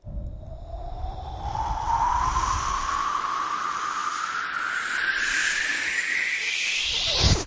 描述：向上的呜呜声4小节135bpm
Tag: 创意 敢-19 循环 口技